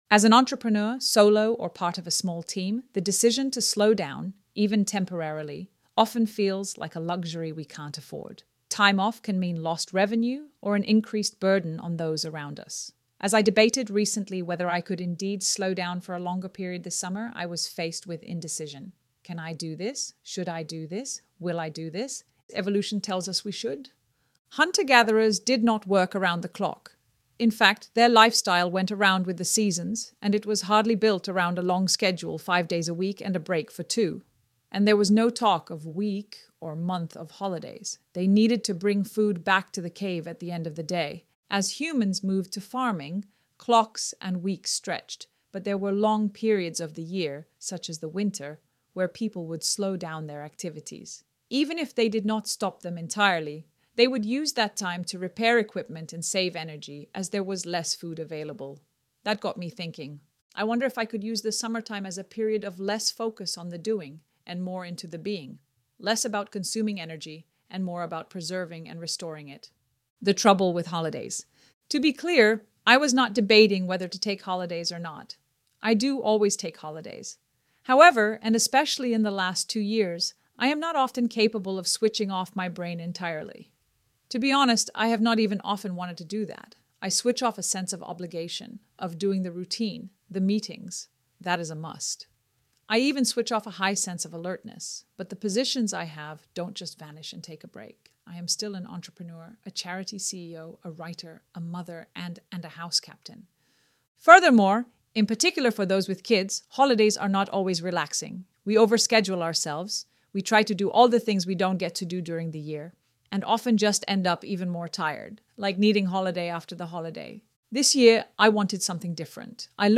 ElevenLabs_Summer_Slowdown.mp3